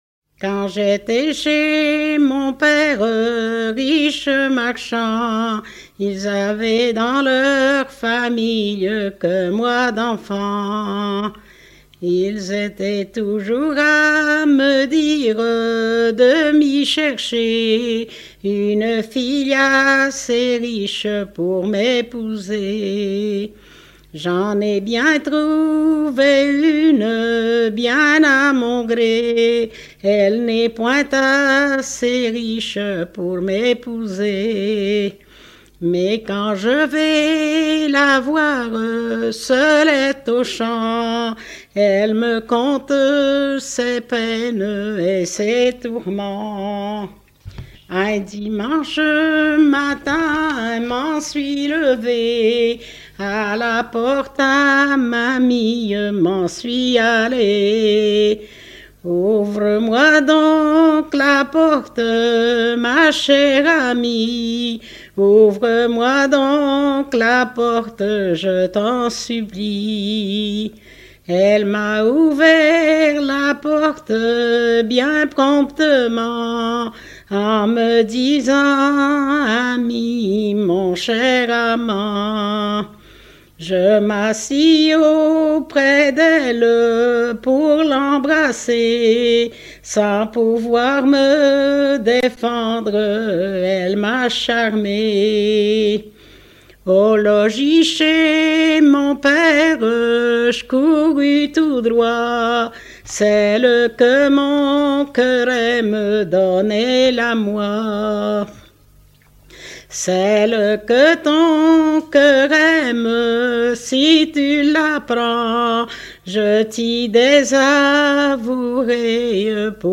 Localisation Châteauneuf
Genre strophique